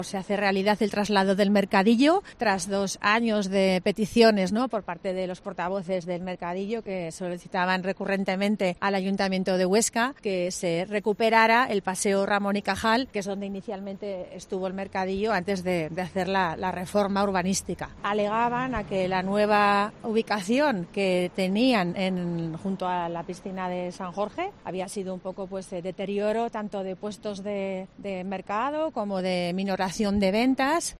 La alcaldesa de Huesca, Lorena Orduna anuncia el traslado del mercadillo